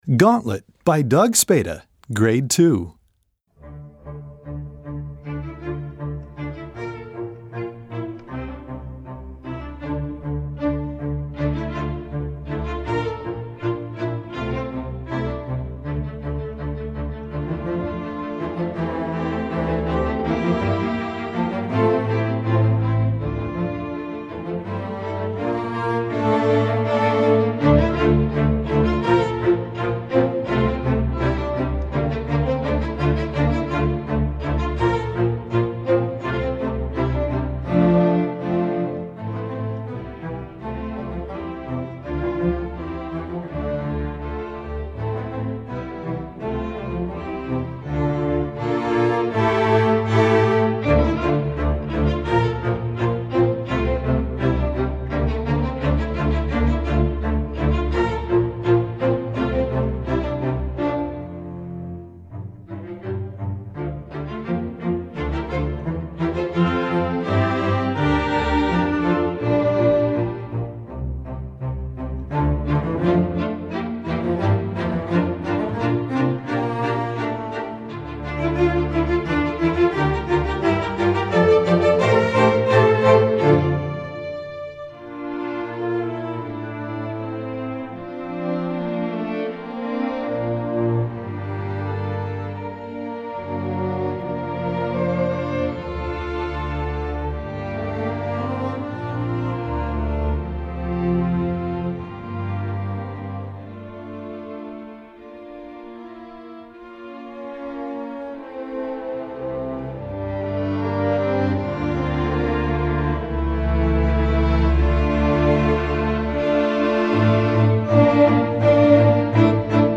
Gattung: Streichorchester
Besetzung: Streichorchester